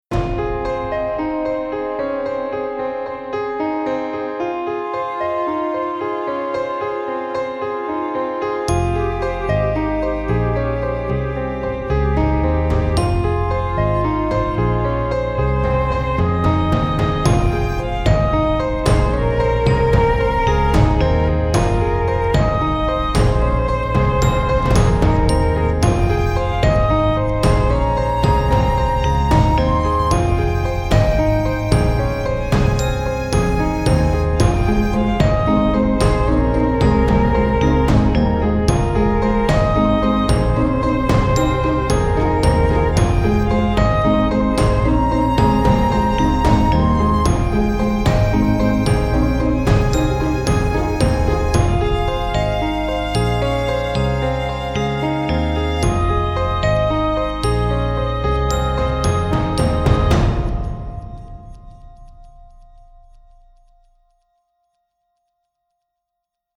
It’s only a minute long, and was written for piano, strings, glockenspiel, and some percussion.